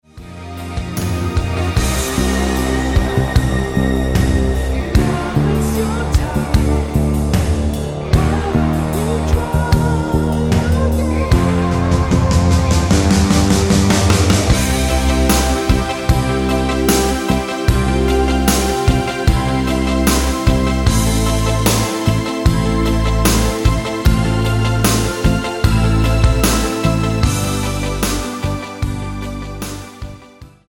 --> MP3 Demo abspielen...
Tonart:Bb-C mit Chor